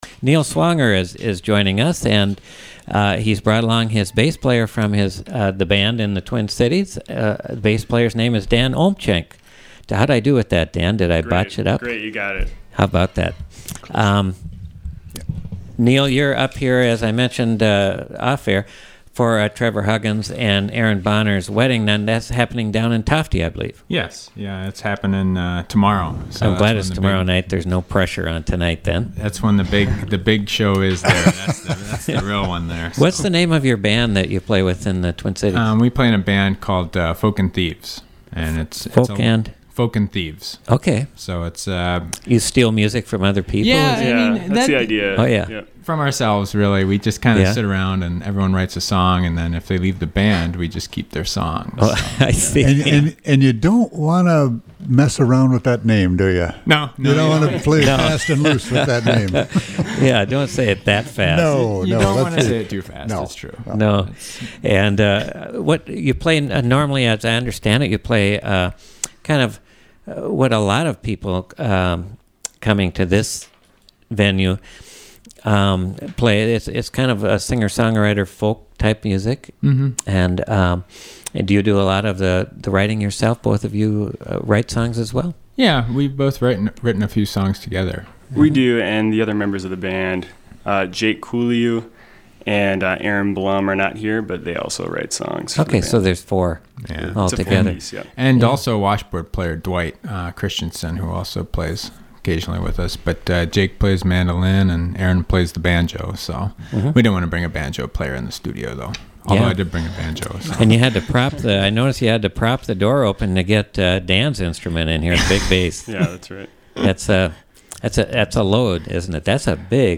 bass player
Studio A